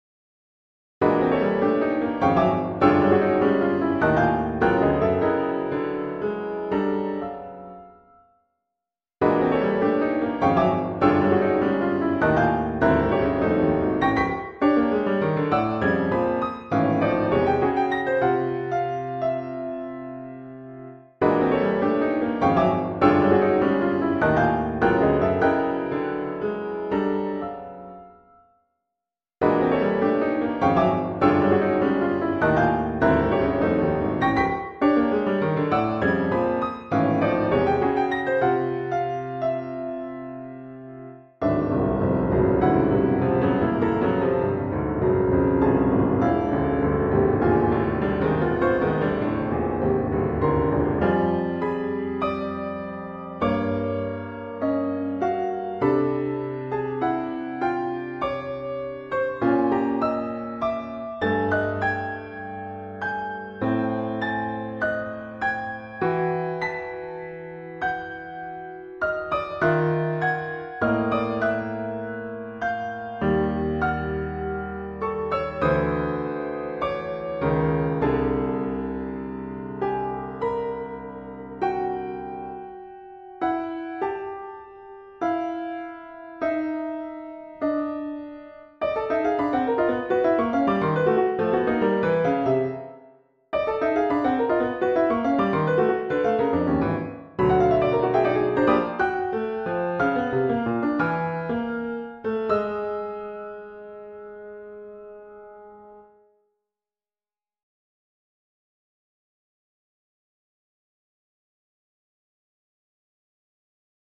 Works for automatic piano(1998)  8:27